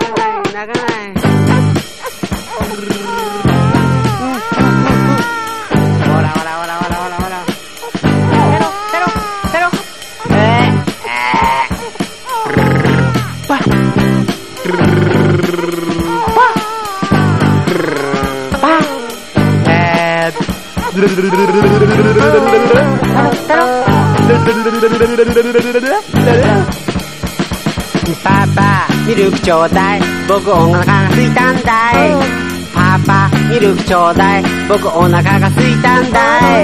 黄金色に輝くフィラデルフィア産ノーザン・ソウル・ダンサー＆甘茶ソウル・クラシックを収録した編集盤！
ガール・ポップ～オールディーズ・ファンも虜にしてしまう甘酸っぱい魅惑のコーラス・ワークがホント極上！